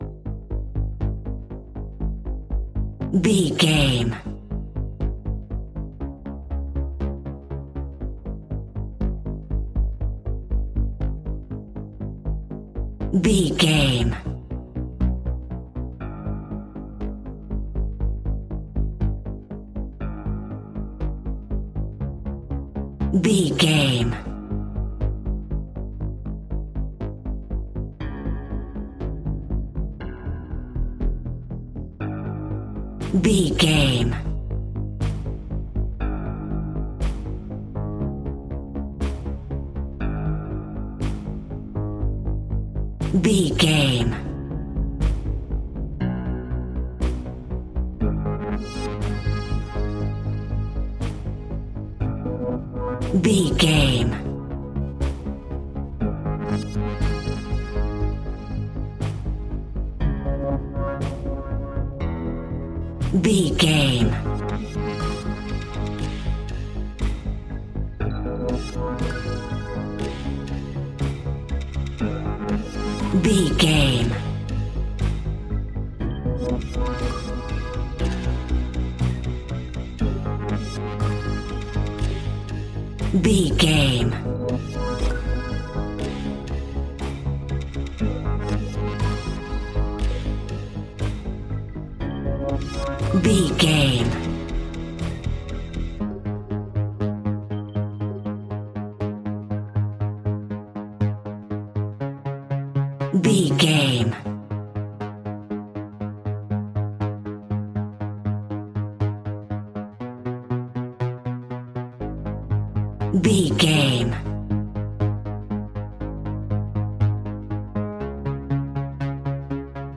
In-crescendo
Thriller
Aeolian/Minor
ominous
dark
haunting
eerie
horror music
Horror Pads
horror piano
Horror Synths